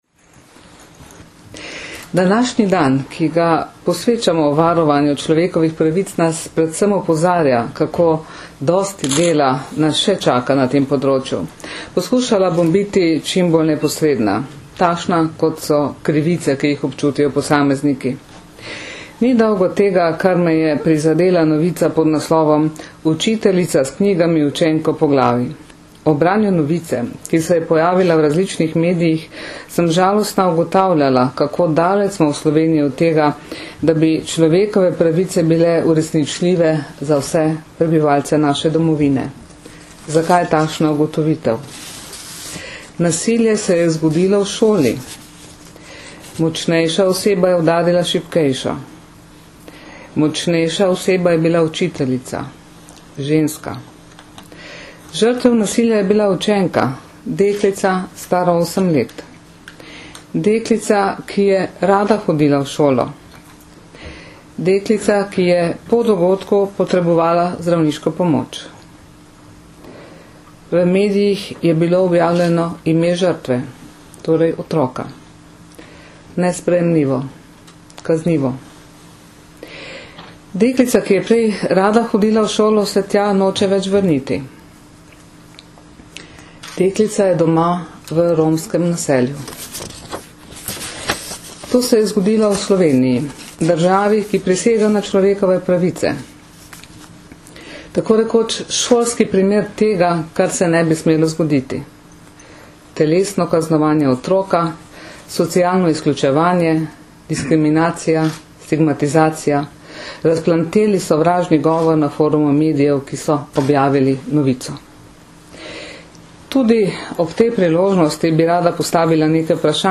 Nagovor varuhinje človekovih pravic